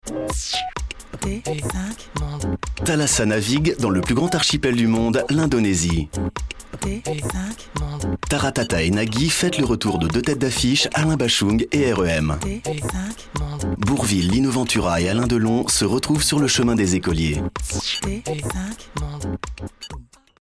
Voix off pro depuis janvier 2007,publicités et annonces radio et TV.
Sprecher französisch Voix off tonique, médium-grave, ou autre selon votre demande ...
Sprechprobe: Werbung (Muttersprache):